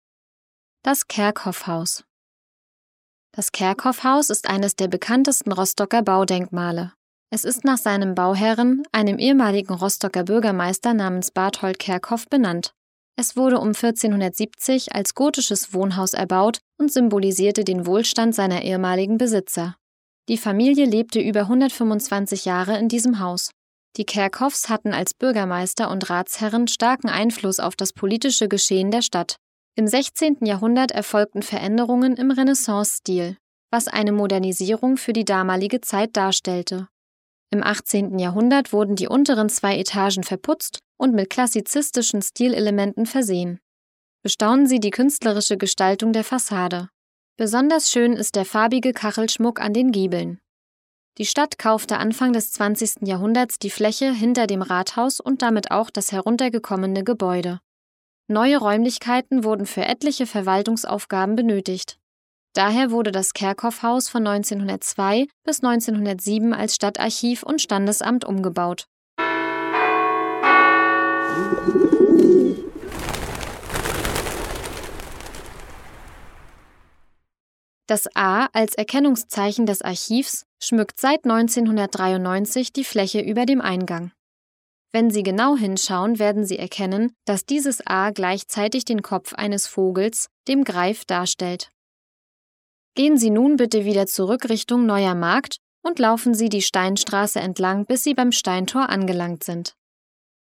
Audioguide Rostock - Station 11: Kerkhoffhaus
11-rostock-de-kerkhoffhaus.mp3